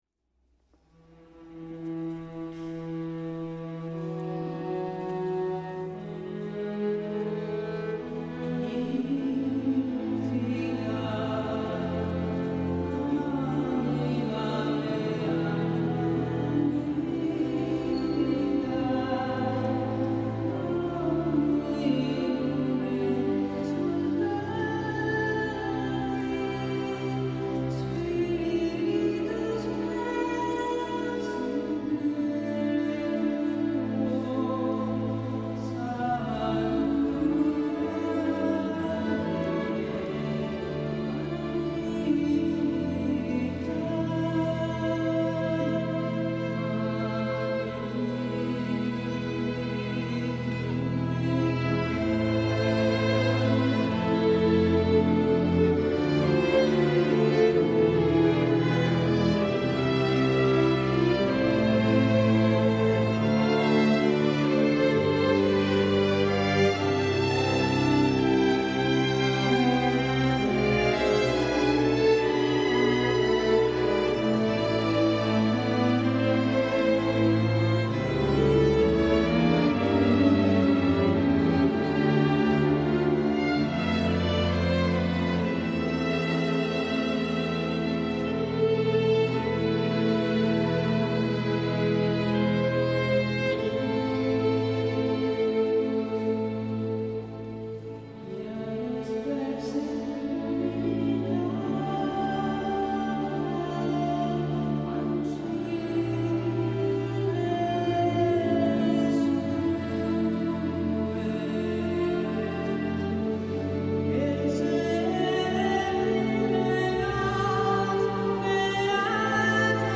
Sabato 05 maggio 2012 la corale ha eseguito la prima edizione del Concerto di S. Eurosia, in collaborazione con l'orchestra "L'Incanto Armonico" di Pisogne (BS).